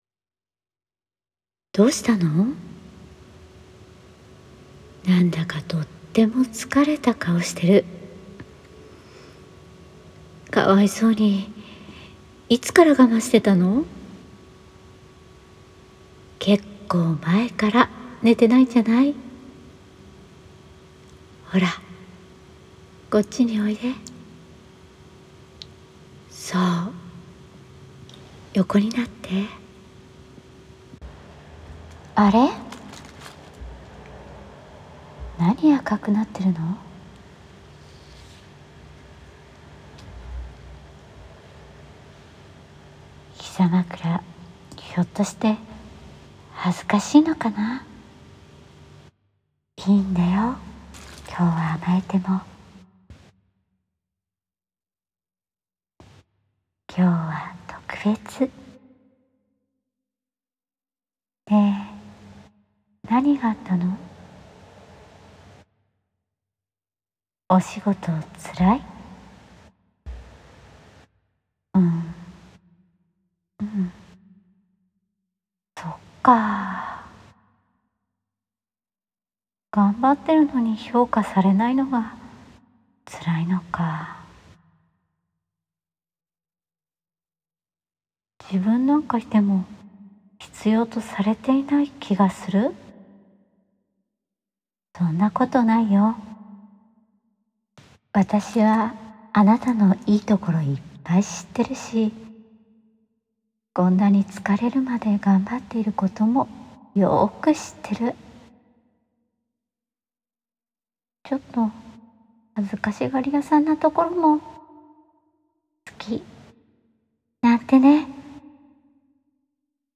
疲れているあなたへ 疲れを取る癒しボイス
治愈
ASMR